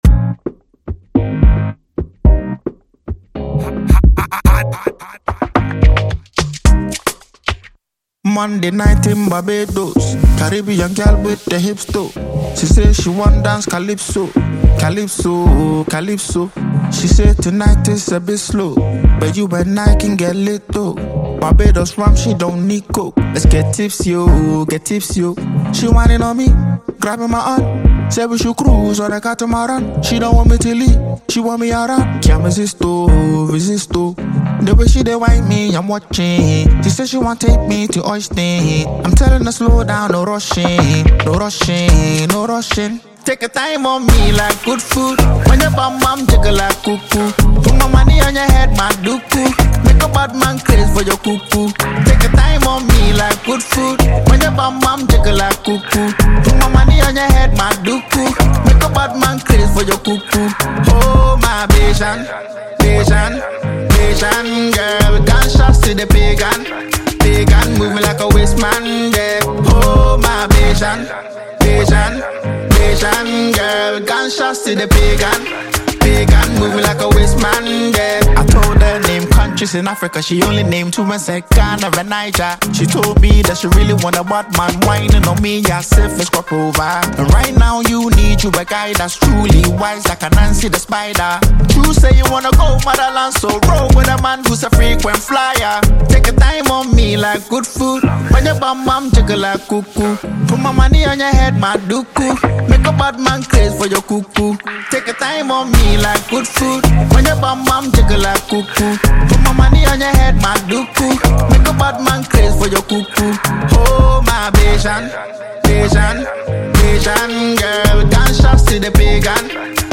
• Genre: Afrobeats / Afropop